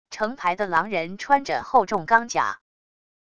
成排的狼人穿着厚重钢甲wav音频